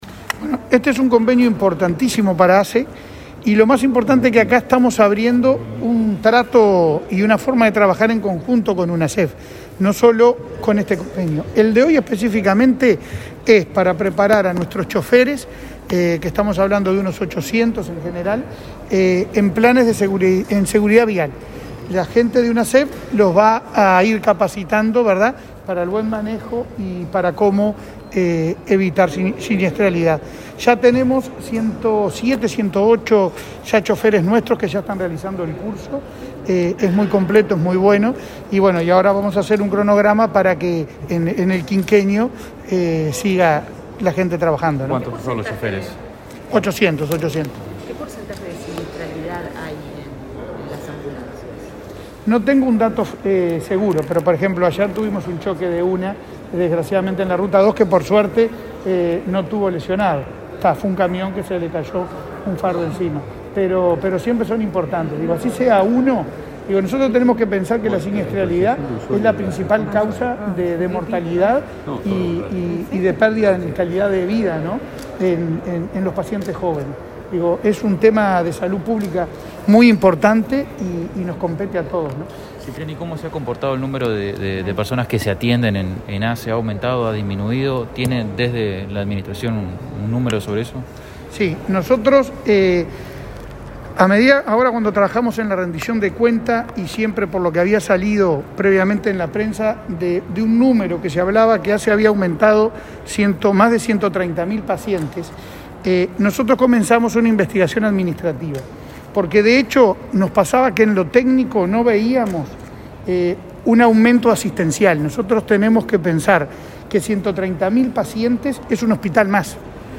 Declaraciones del presidente de ASSE, Leonardo Cipriani
Declaraciones del presidente de ASSE, Leonardo Cipriani 11/08/2021 Compartir Facebook X Copiar enlace WhatsApp LinkedIn La Unidad Nacional de Seguridad Vial (Unasev) y la Administración de los Servicios de Salud del Estado (ASSE) acordaron, este miércoles 11, un convenio marco de cooperación por el cual trabajarán en proyectos articulados entre ambos organismos. En la actividad, el presidente de ASSE, Leonardo Cipriani, brindó declaraciones a los medios de comunicación para detallar el acuerdo.